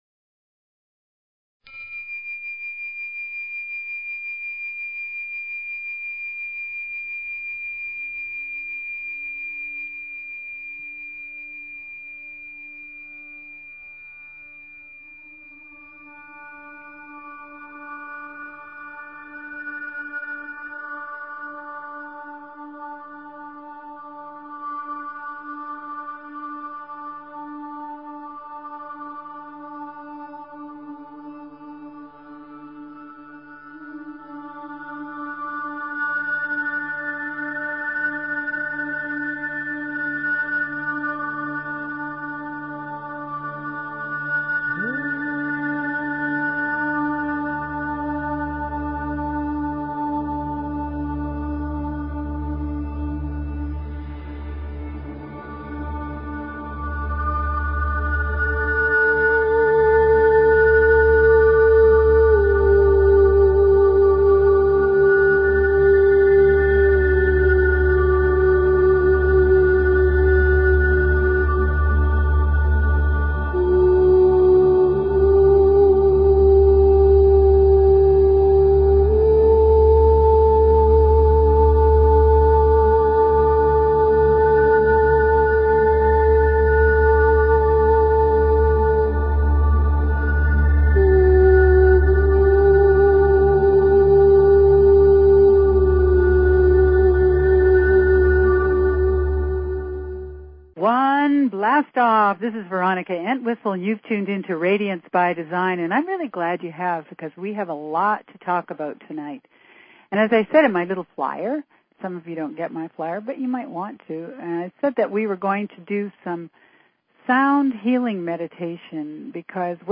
Talk Show Episode, Audio Podcast, Radiance_by_Design and Courtesy of BBS Radio on , show guests , about , categorized as
This is a call in show so call in!